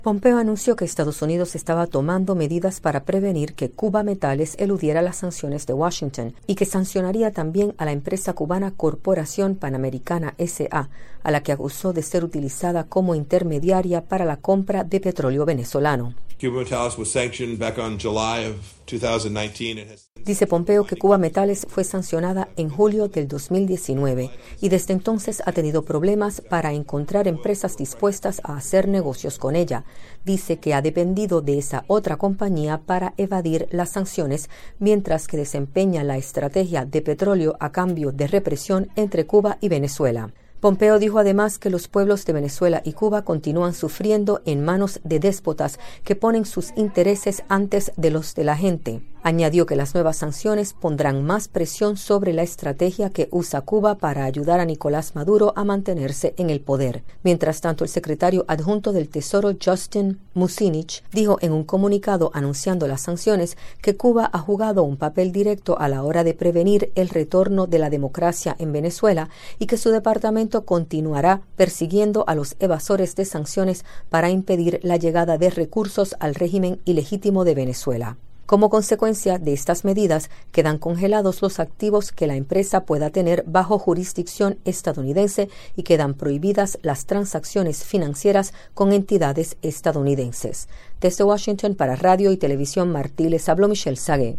“Hoy Estados Unidos está actuando para evitar que Cubametales pueda eludir las sanciones”, dijo el Secretario de Estado, Mike Pompeo, durante una conferencia de prensa en la sede del Departamento de Estado.